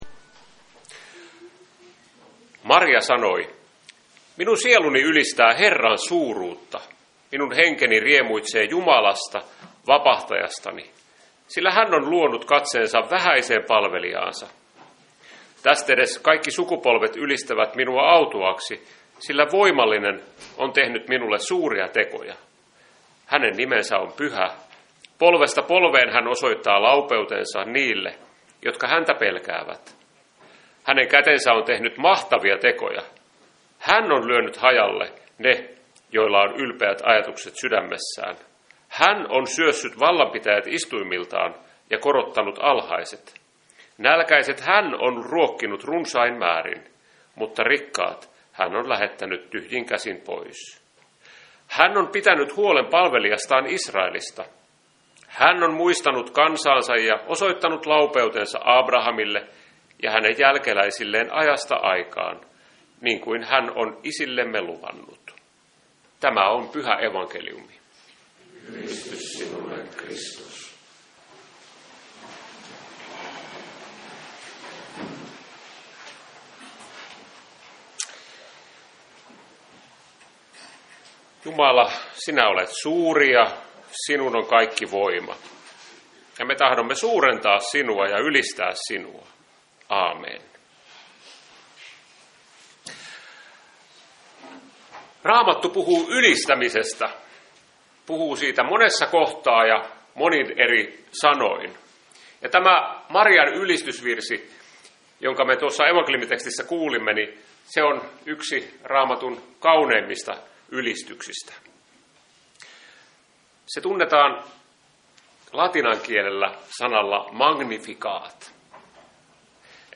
Messu Seinäjoen Hyvän Paimenen Kappelilla 22.3.2026